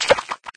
somen_splash.ogg